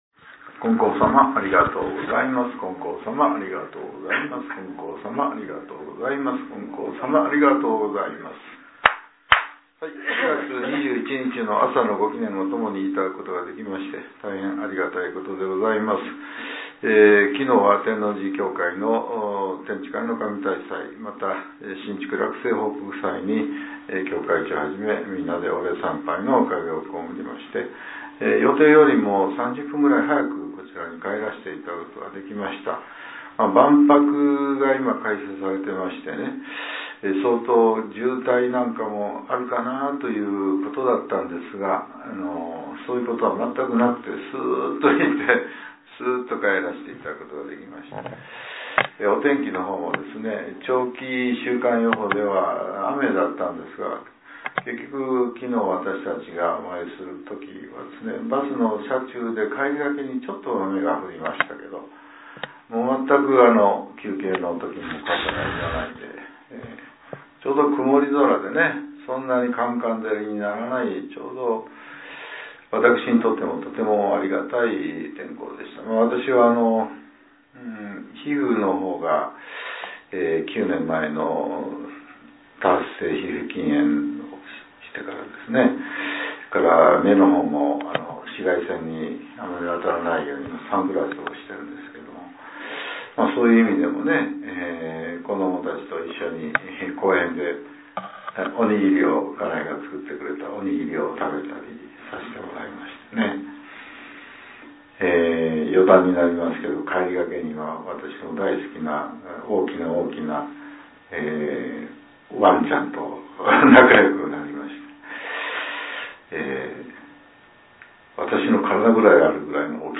令和７年４月２１日（朝）のお話が、音声ブログとして更新されています。